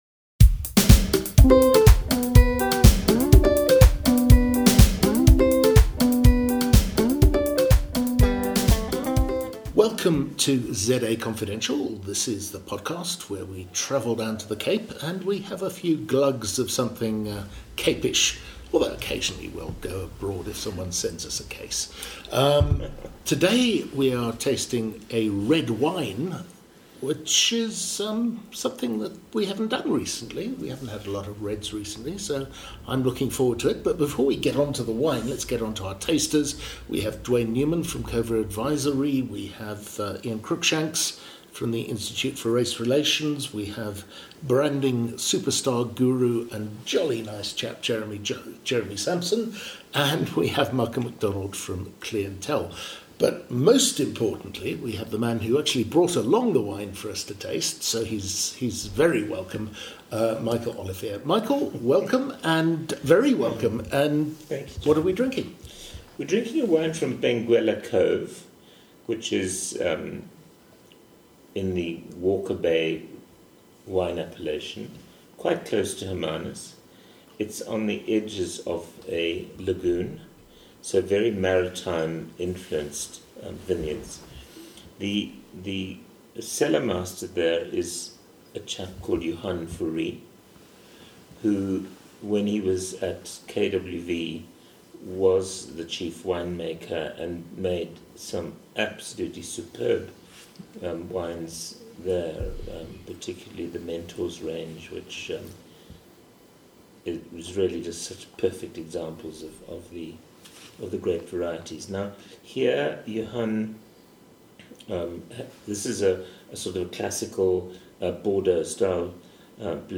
Today’s Cape wine tasting podcast goes terracotta potty.